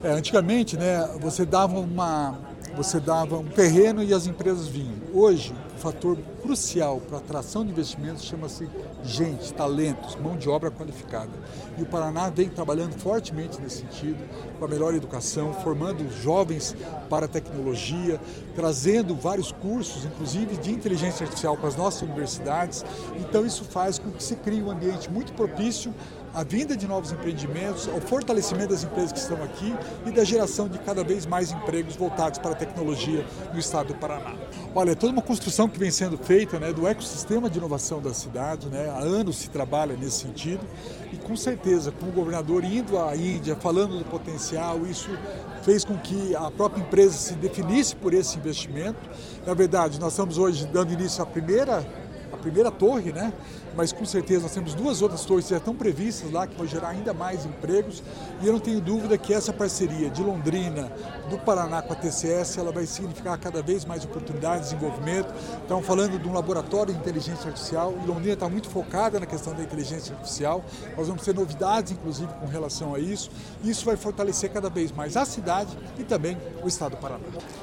Sonora do secretário da Inovação e Inteligência Artificial, Alex Canziani, sobre o anúncio de novo campus da TCS em Londrina